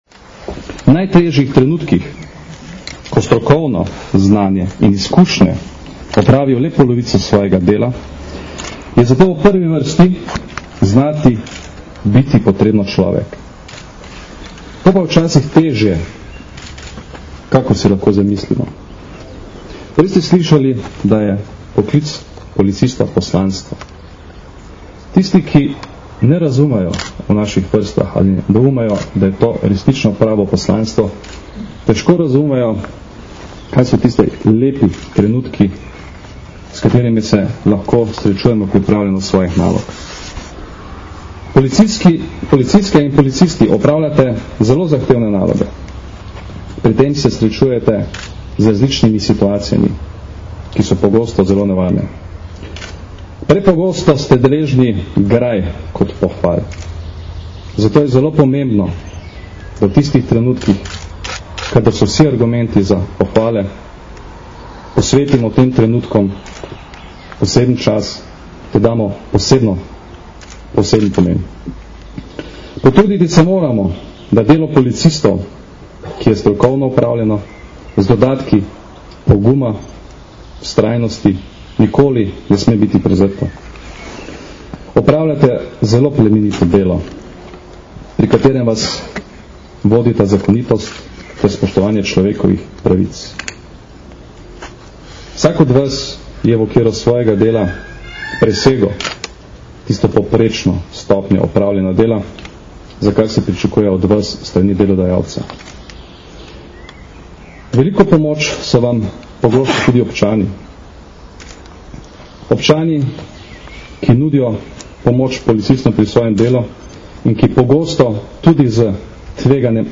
V Policijski akademiji v Tacnu sta generalni direktor policije Janko Goršek in ministrica za notranje zadeve Katarina Kresal danes, 24. maja 2010, sprejela 27 policistov in šest občanov ter jim podelila medalje policije za hrabrost in požrtvovalnost.
Zbrane je nagovoril tudi generalni direktor policije Janko Goršek, ki se je vsem zahvali za bodisi izjemen pogum, nesebičnost ali pa več kot strokovno opravljanje svojega dela in jim čestital za prejeta priznanja.
Zvočni posnetek govora Janka Gorška (mp3)